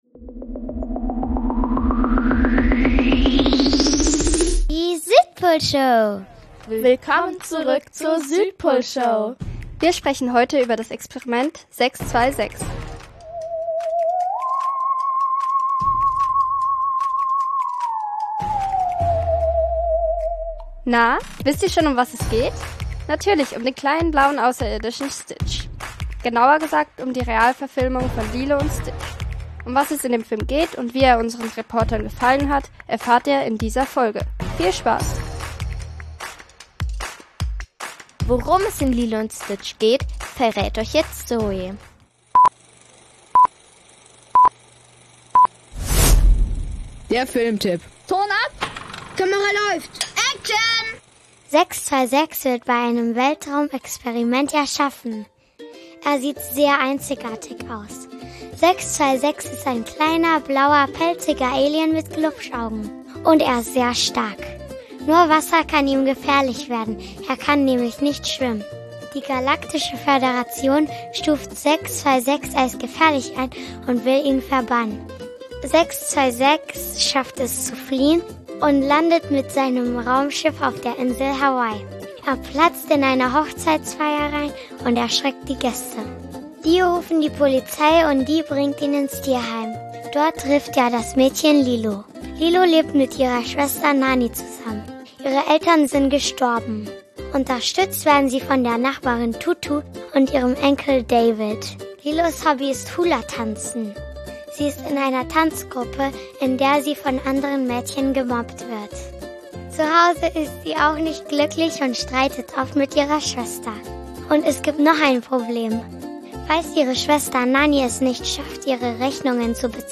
Realverfilmung mit echten Darstellern. Meinungen der Reporter und der Kino Besucher.
Wir waren beim "Lilo & Stitch" Fan Event im Münchner ARRI Kino